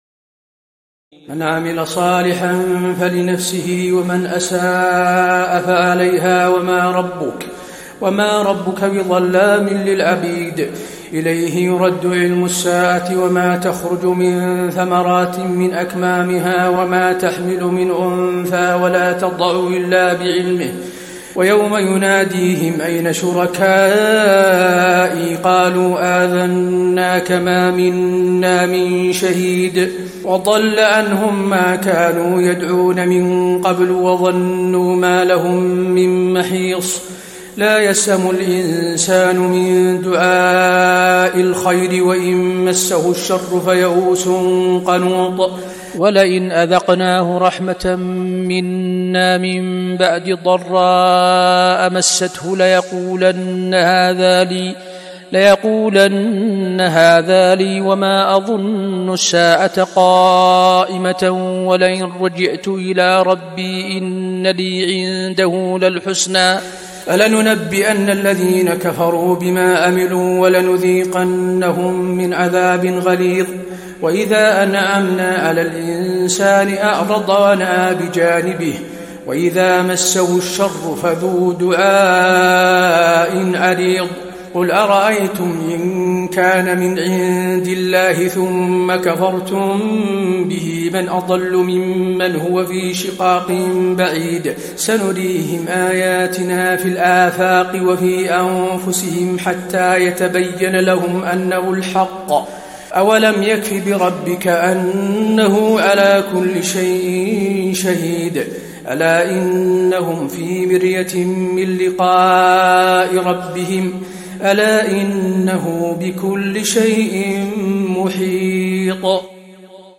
تراويح ليلة 24 رمضان 1435هـ من سور فصلت (46-54) و الشورى و الزخرف (1-25) Taraweeh 24 st night Ramadan 1435H from Surah Fussilat and Ash-Shura and Az-Zukhruf > تراويح الحرم النبوي عام 1435 🕌 > التراويح - تلاوات الحرمين